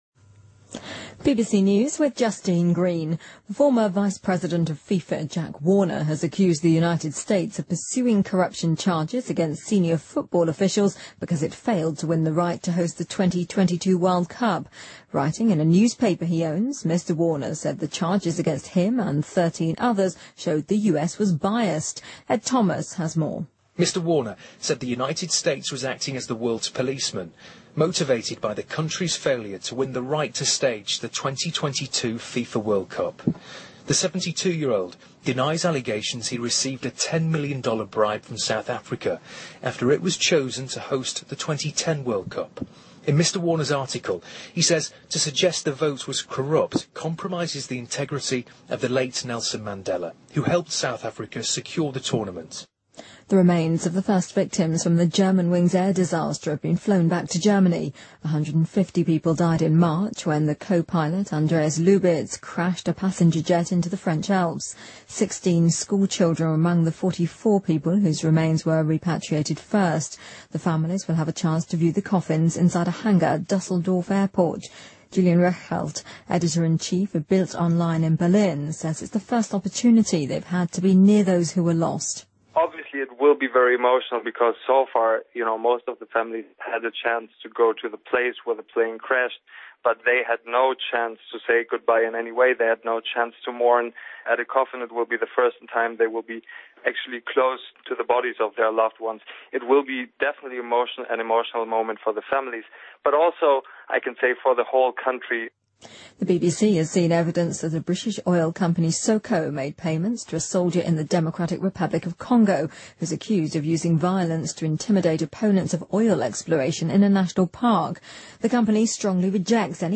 BBC news,沃纳指责美国因未能赢得2022年世界杯举办权而报复国际足联